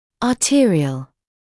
[ɑː’tɪərɪəl][аː’тиэриэл]артериальный